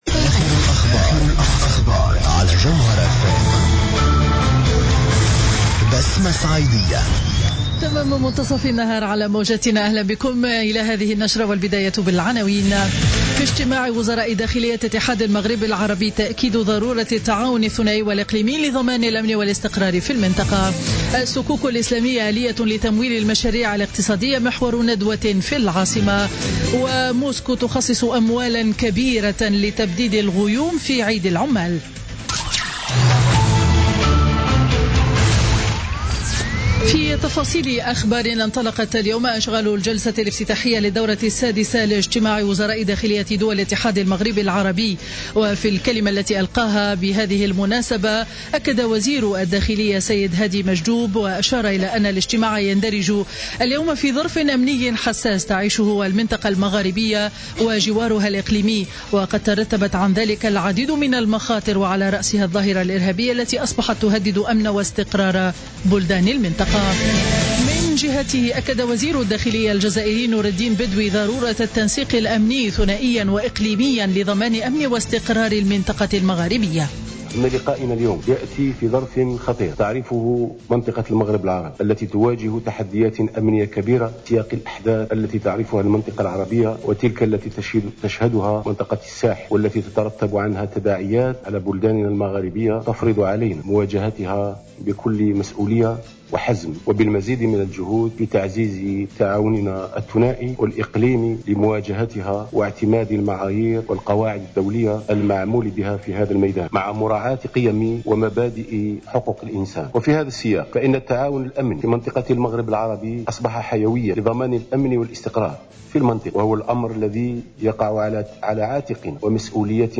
نشرة أخبار منتصف النهار ليوم الاثنين 25 أفريل 2016